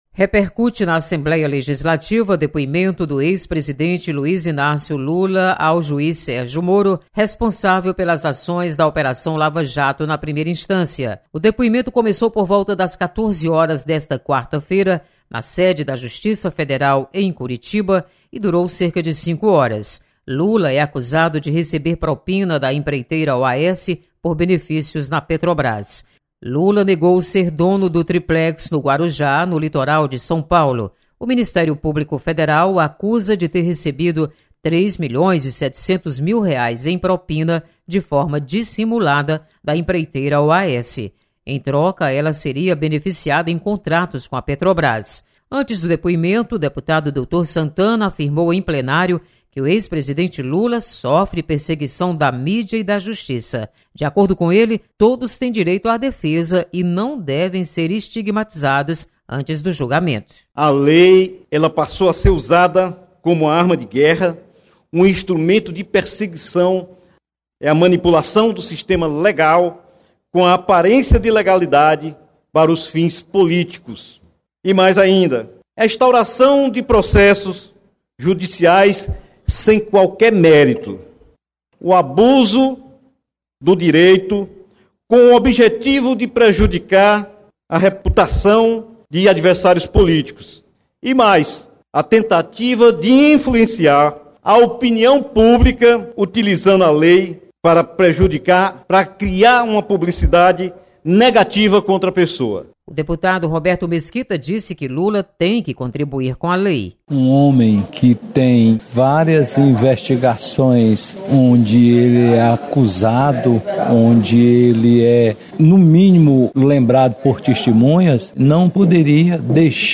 Deputados comentam depoimento do ex-presidente Lula.